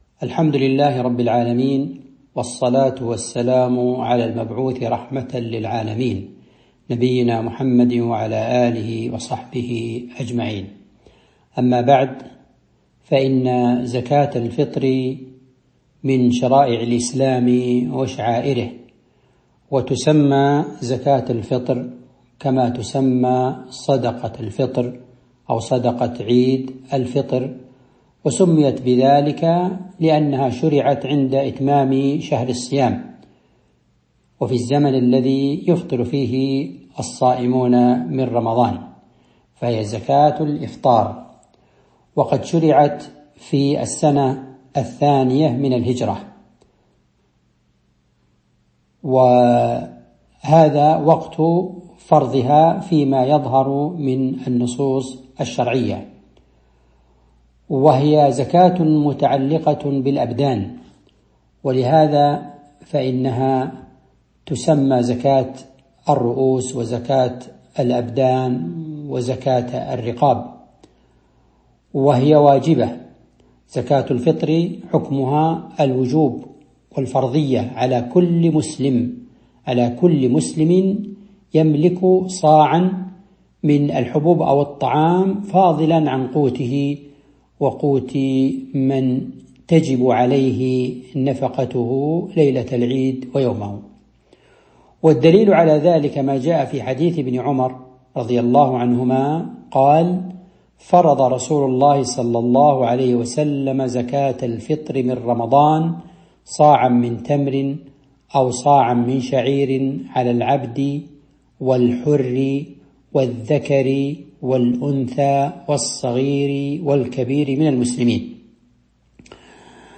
تاريخ النشر ٢٥ رمضان ١٤٤٣ هـ المكان: المسجد النبوي الشيخ